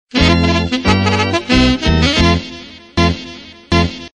알림 음